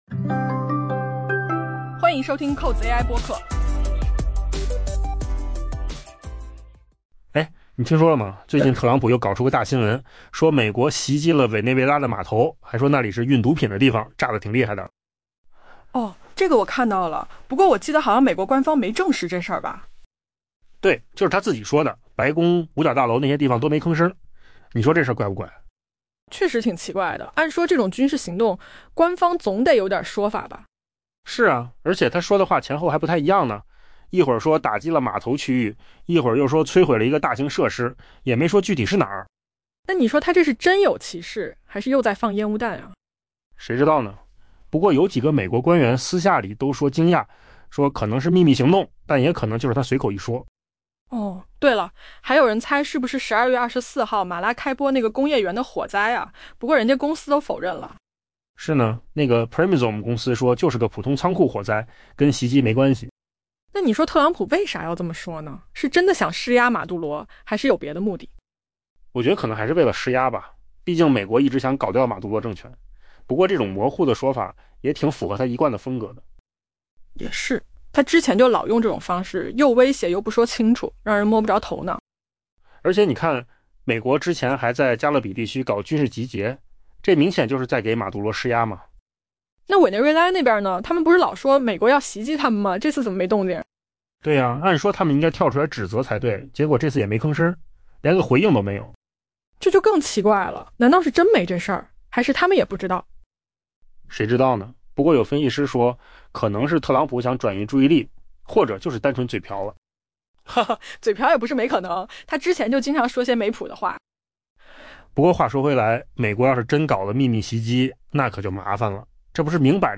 AI 播客：换个方式听新闻 下载 mp3 音频由扣子空间生成 美国总统特朗普周一表示，美国最近对委内瑞拉一处码头区域发动了袭击，该处是毒品被装上船只并贩运至国际水域的地点，他声称那里发生了 「大爆炸」。